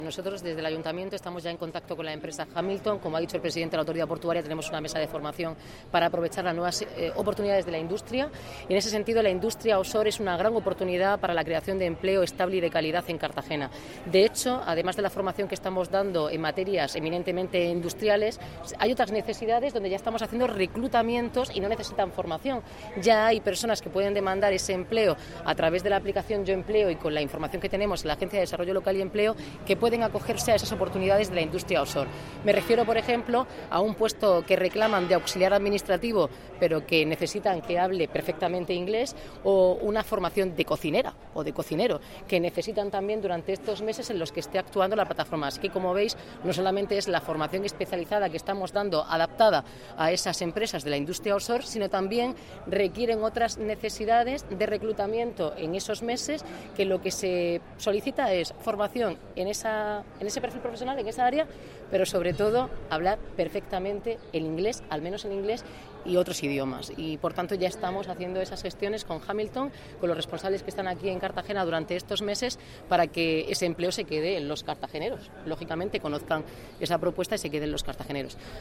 Enlace a Declaraciones de la alcaldesa, Noelia Arroyo.